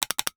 NOTIFICATION_Click_02_mono.wav